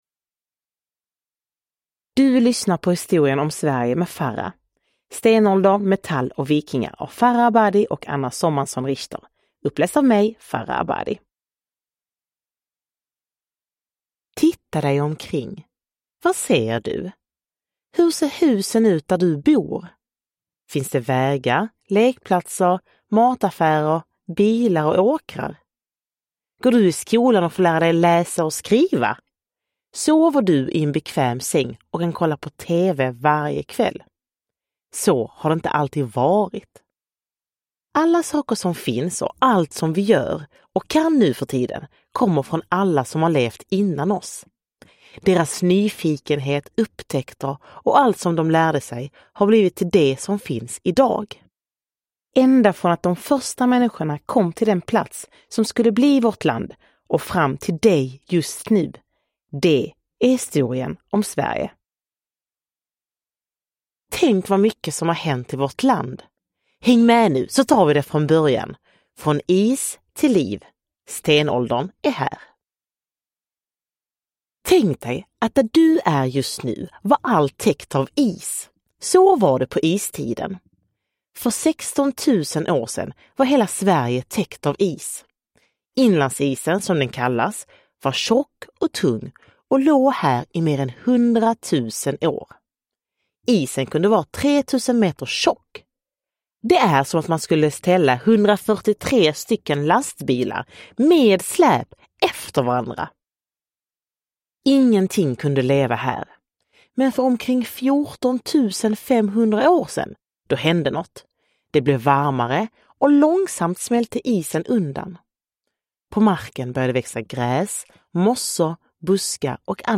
Uppläsare: Farah Abadi
Ljudbok